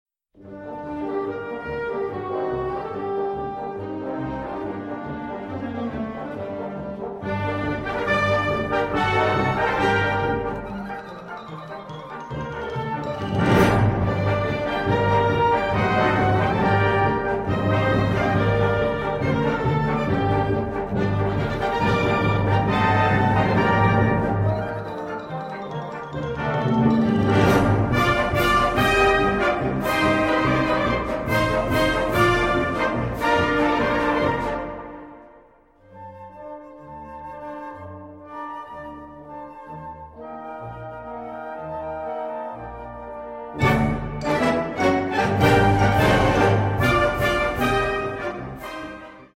G Minor（原調）